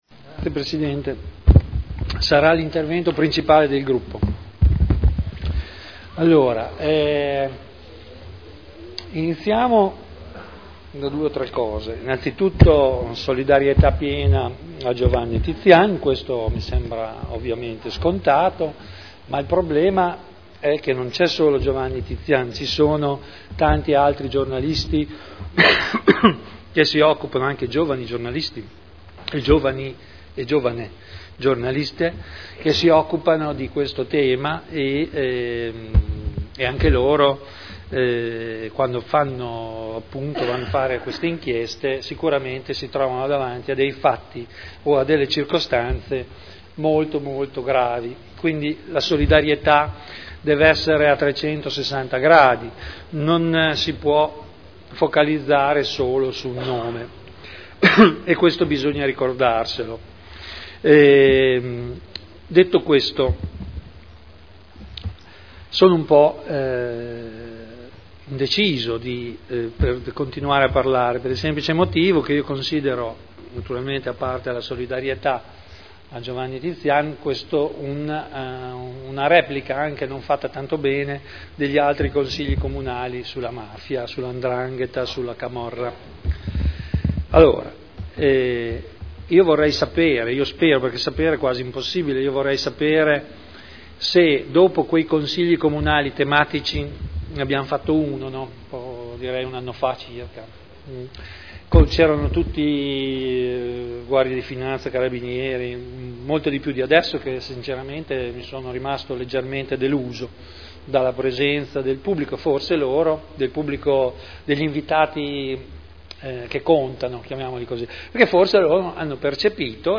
Vittorio Ballestrazzi — Sito Audio Consiglio Comunale